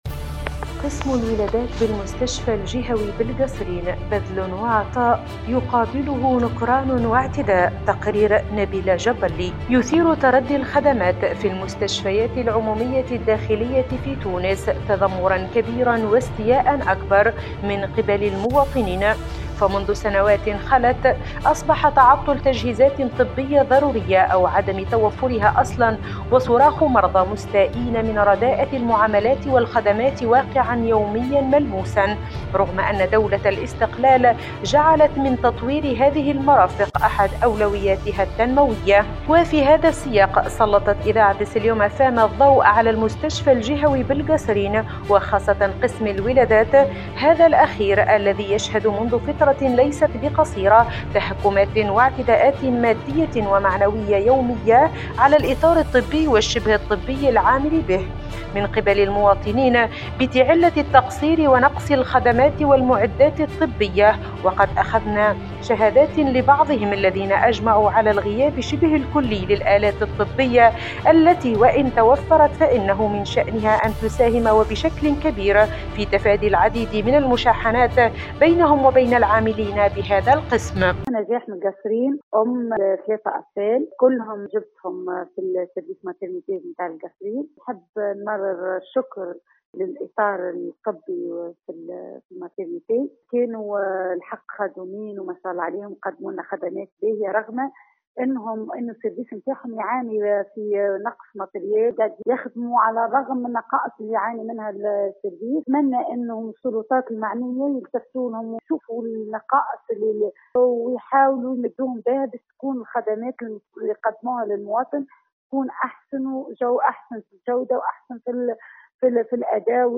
في هذا الاطار نمرر هذا التقرير